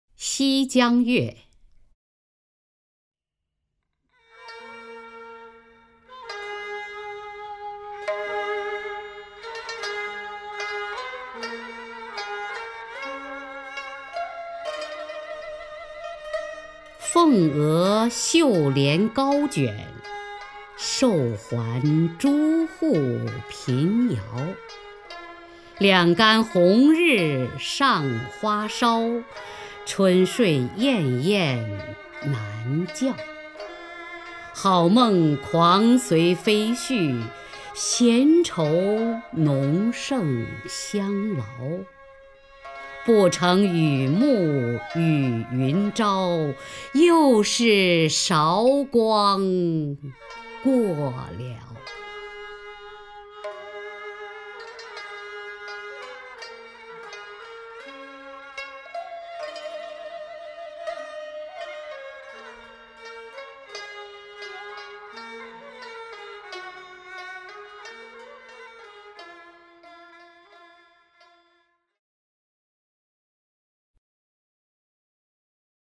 首页 视听 名家朗诵欣赏 雅坤
雅坤朗诵：《西江月·凤额绣帘高卷》(（北宋）柳永)
XiJiangYueFengEXiuLianGaoJuan_LiuYong(YaKun).mp3